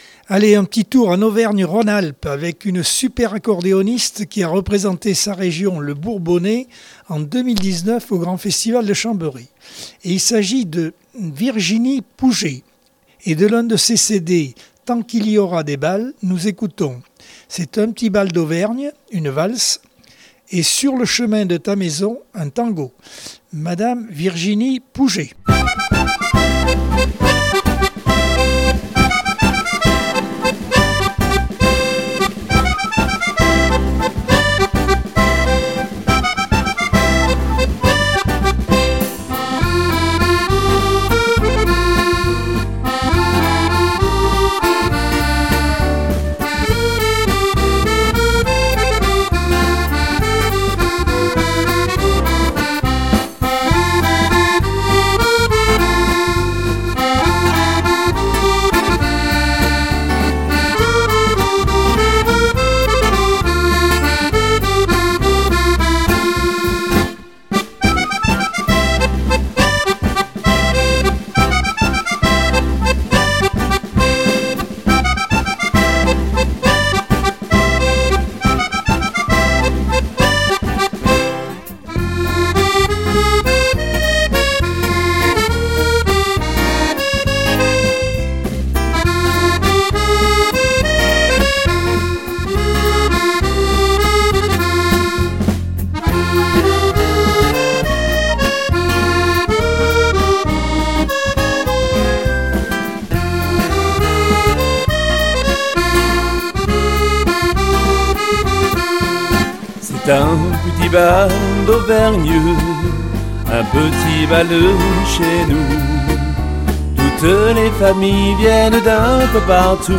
Accordeon 2022 sem 01 bloc 2 - Radio ACX